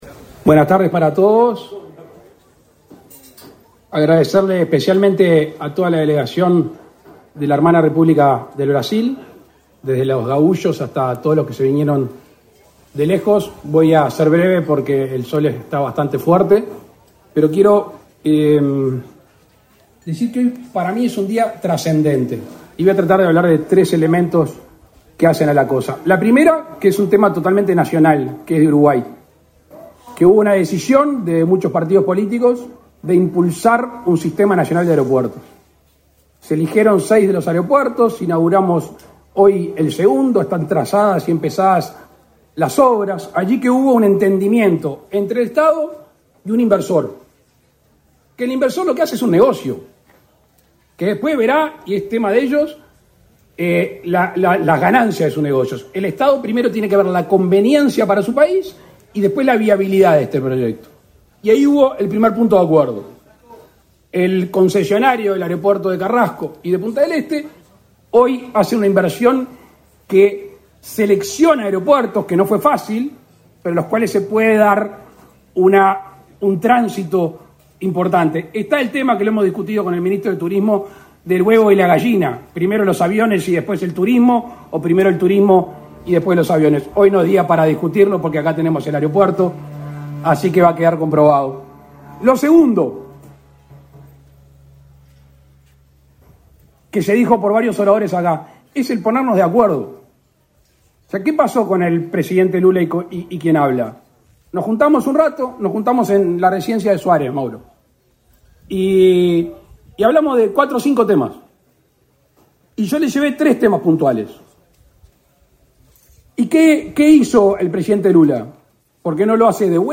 Palabras del presidente de la República, Luis Lacalle Pou, en inauguración de aeropuerto de Rivera